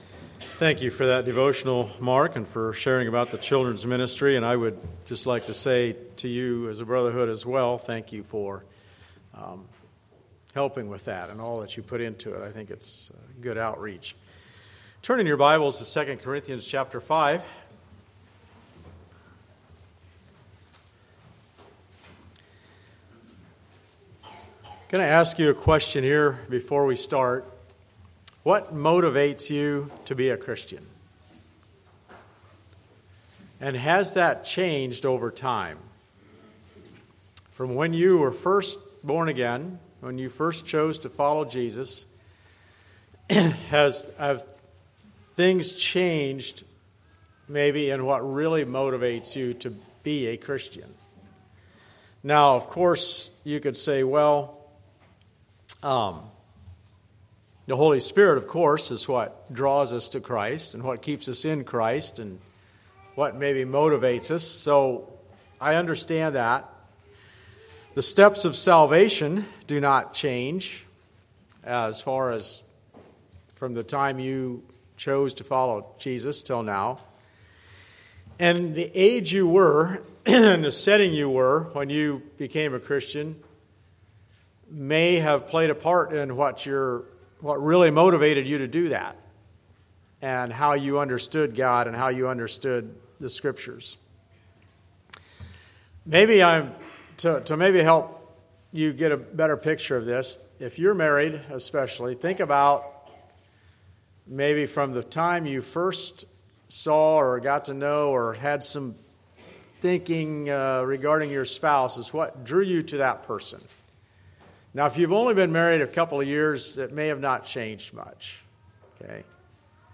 Like this sermon?